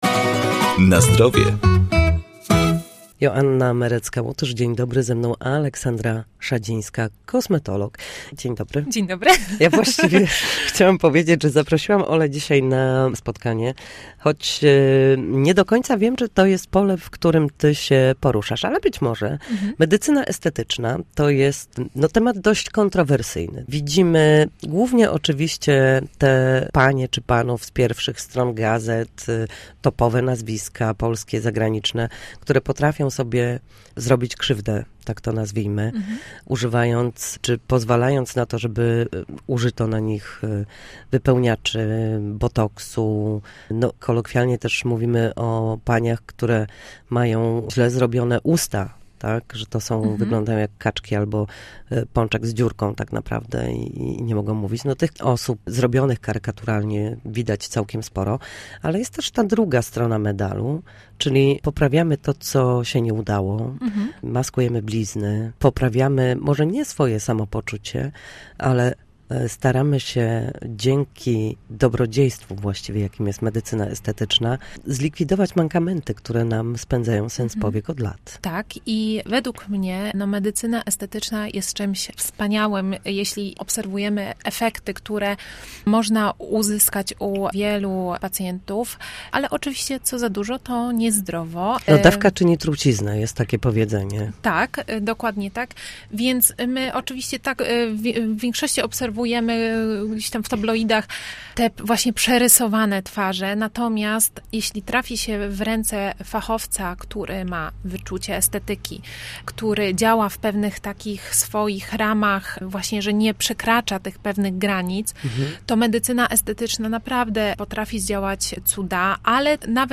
W poniedziałki przedstawiamy na antenie Studia Słupsk sposoby na powrót do formy po chorobach i urazach.